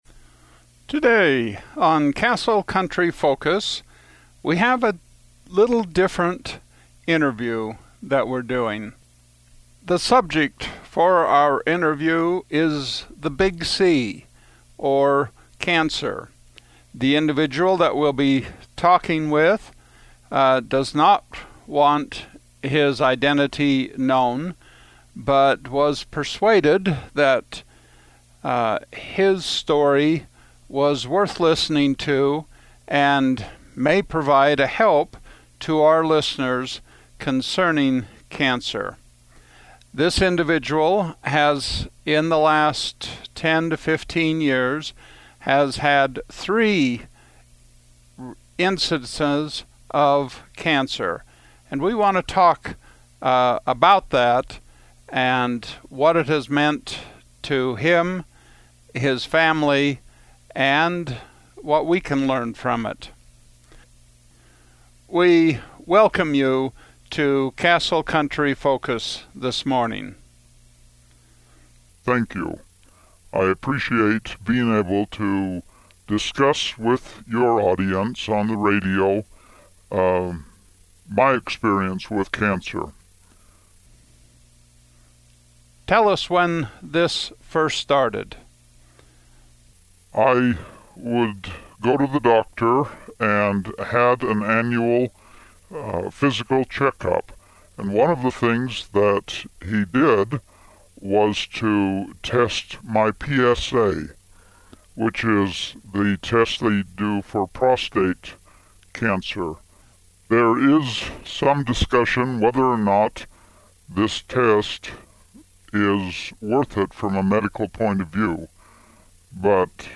This person did not want his identity disclosed, so the voice was altered.
184-Cancer-and-treatment-hidden-voice.mp3